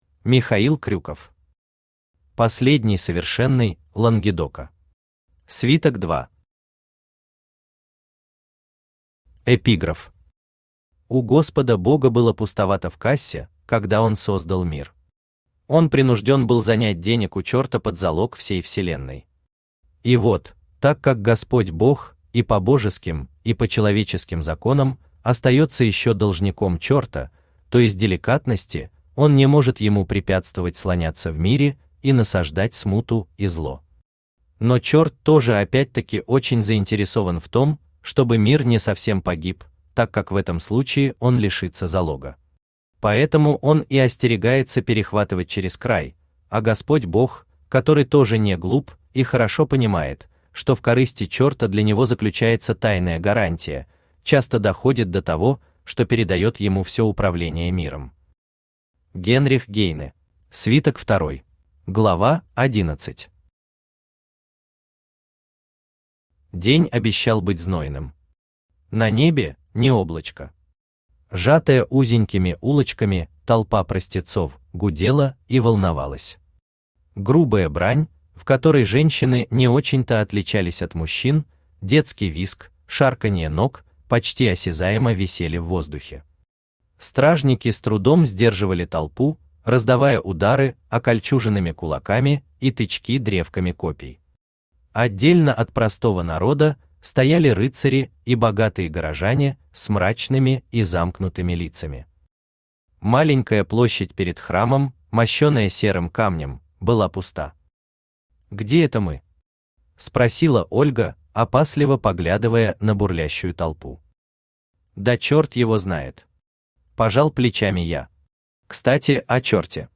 Аудиокнига Последний Совершенный Лангедока. Свиток 2 | Библиотека аудиокниг
Свиток 2 Автор Михаил Крюков Читает аудиокнигу Авточтец ЛитРес.